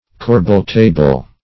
Search Result for " corbel-table" : The Collaborative International Dictionary of English v.0.48: Corbel-table \Cor"bel-ta`ble\, n. (Arch.) A horizontal row of corbels, with the panels or filling between them; also, less properly used to include the stringcourse on them.